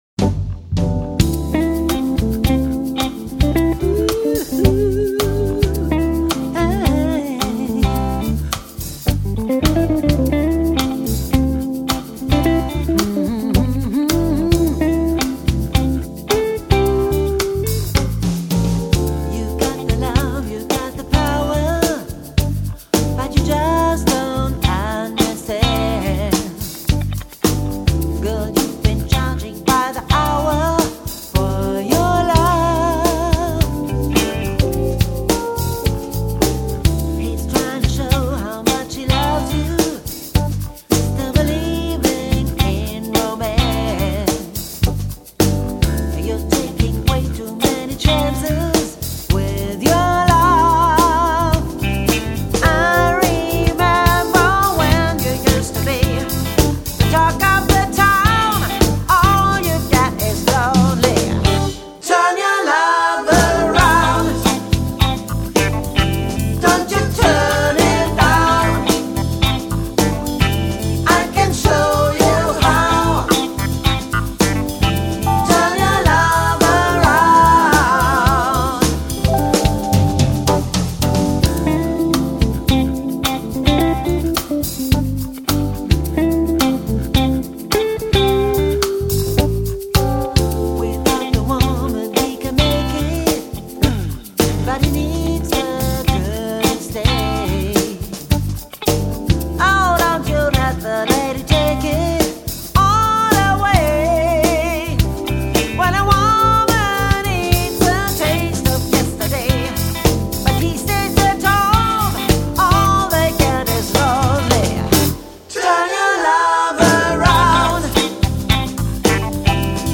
Schöne "smooth" - Version